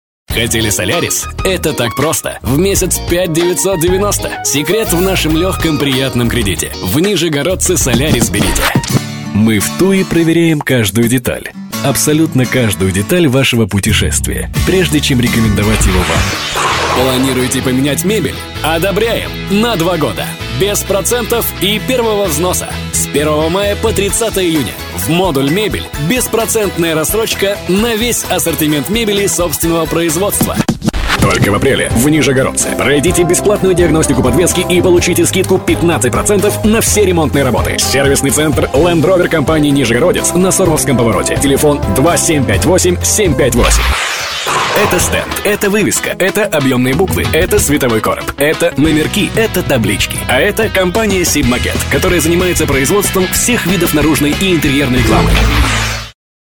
Классный баритон для качественной презентации ваших товаров и услуг. Качественная подача рекламного материала.